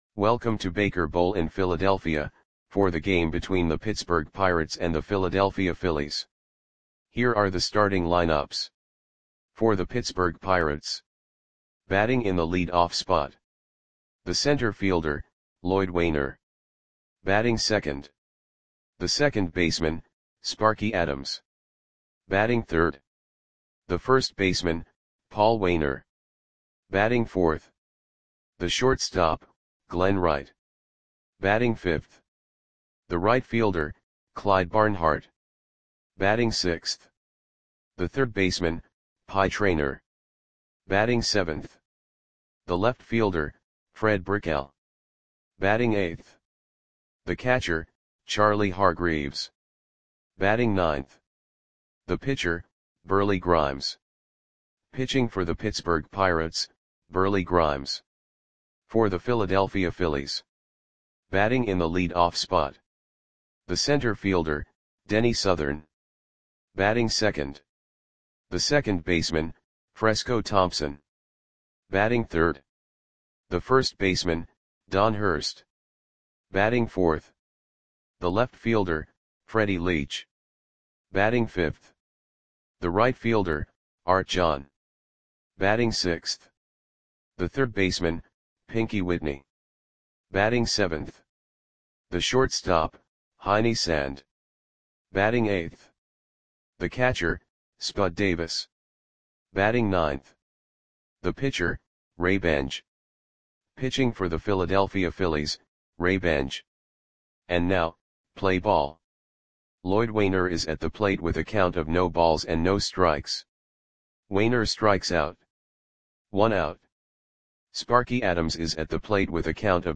Audio Play-by-Play for Philadelphia Phillies on June 13, 1928
Click the button below to listen to the audio play-by-play.